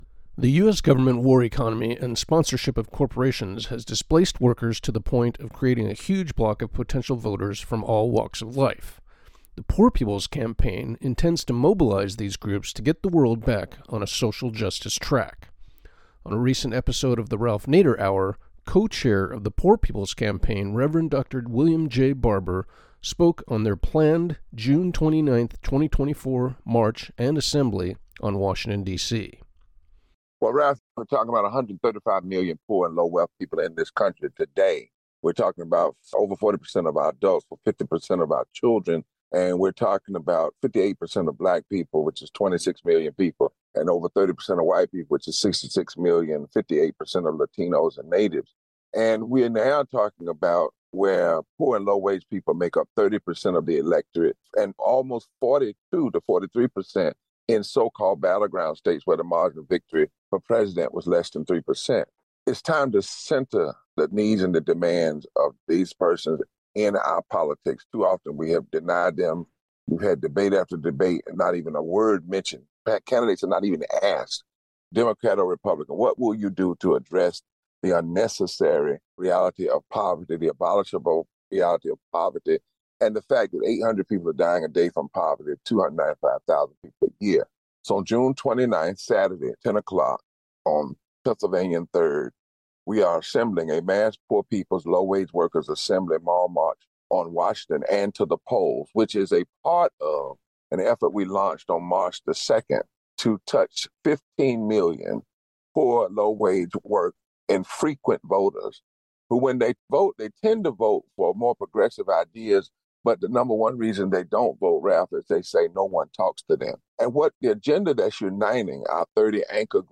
On a recent episode of the Ralph Nader Hour, Co-Chair of the Poor Peoples Campaign Reverend Dr. William J Barber spoke on their planned June 29th 2024 march and assembly on Washington DC.